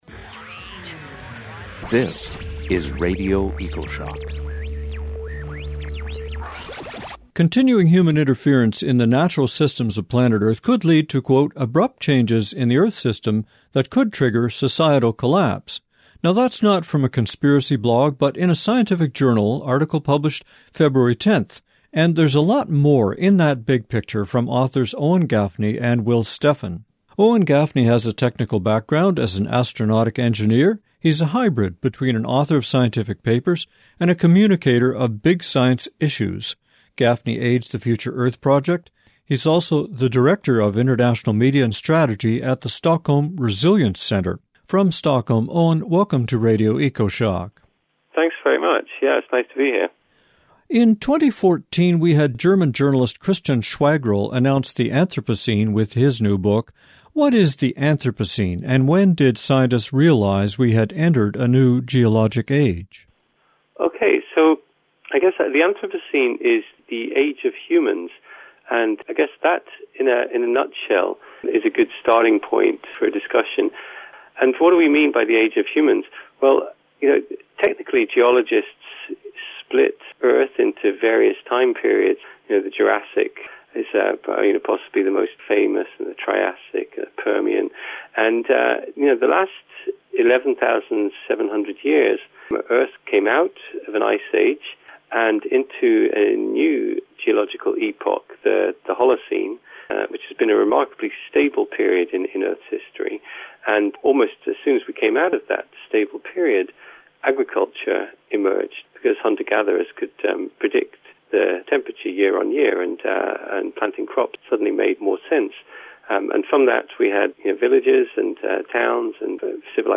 Download or listen to this Radio Ecoshock interview